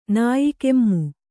♪ nāyi kemmu